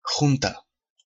In the Napoleonic era, junta (Spanish pronunciation: [ˈxunta]